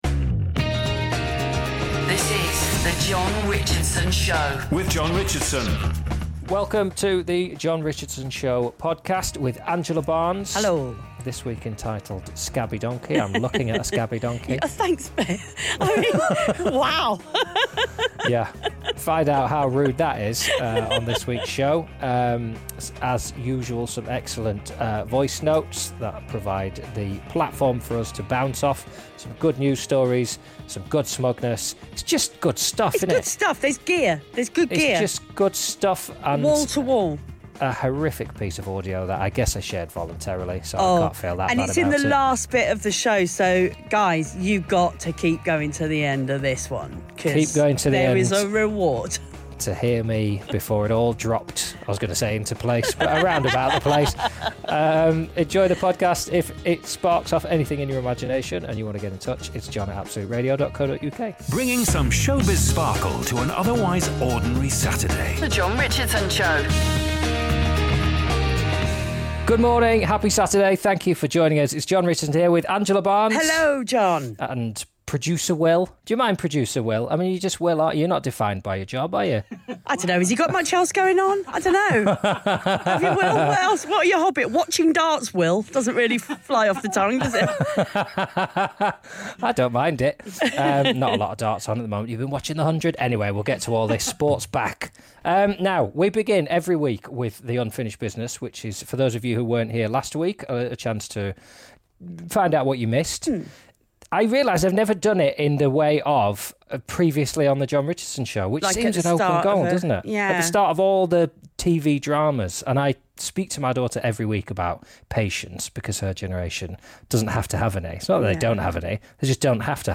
In the feature interview presented by NHL Sense Arena